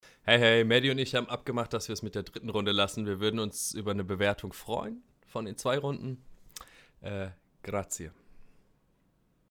seeeeeeeeeeeeeeeeeeeeeeeeeeeeeeeeeeeeeeeeeeeeeeeeeeeeeeeeeeeeeeeeeeeeeeeeeeeeeeeeeeehr schöne Stimme